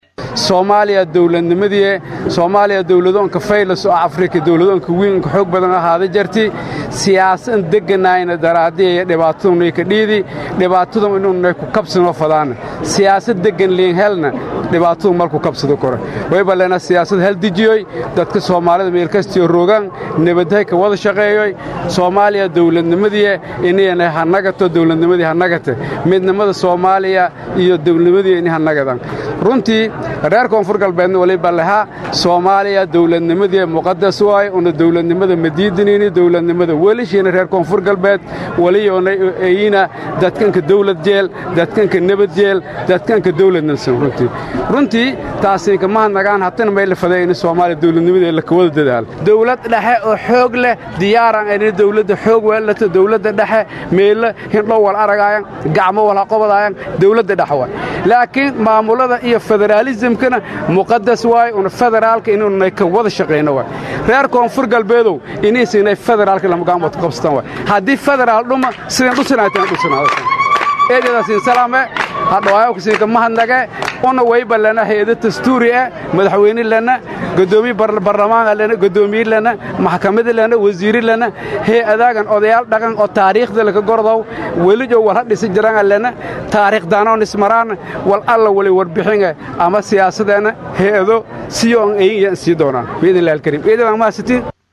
Hoos Ka Dhageyso Codka Madaxweyne Shariif